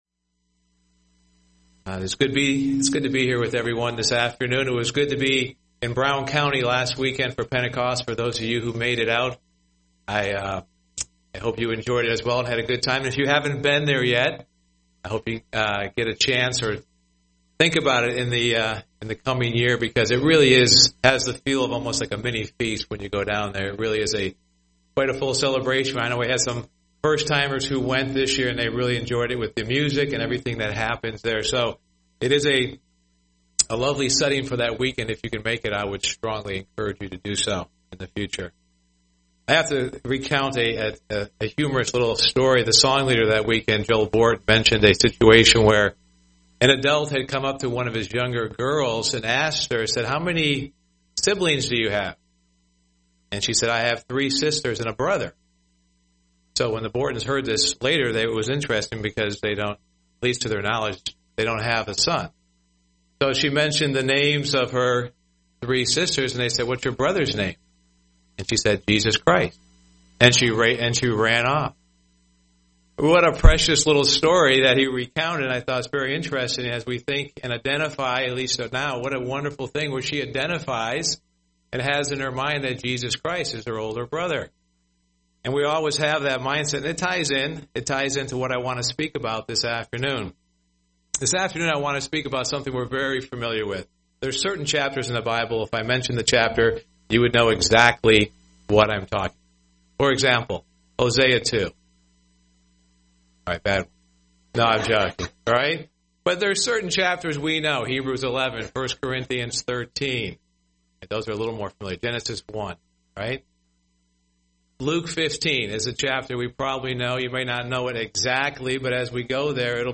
UCG Sermon Notes Luke 15 Things that were lost and redeemed.